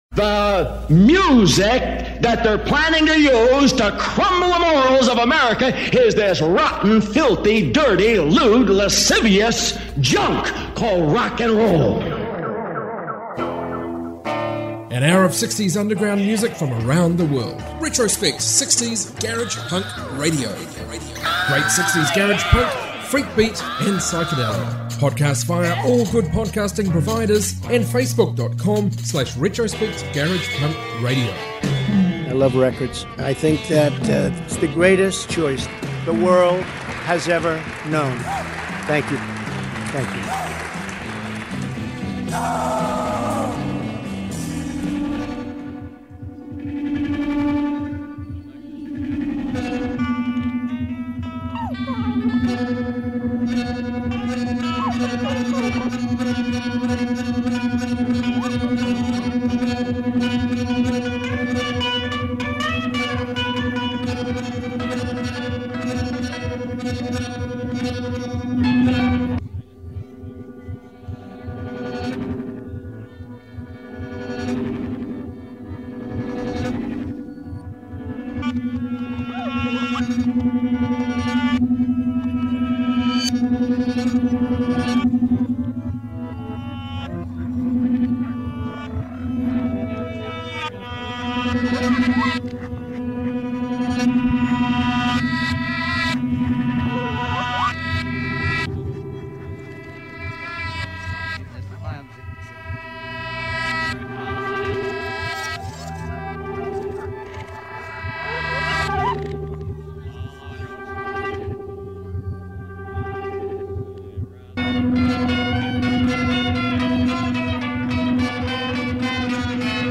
60s garage rock podcast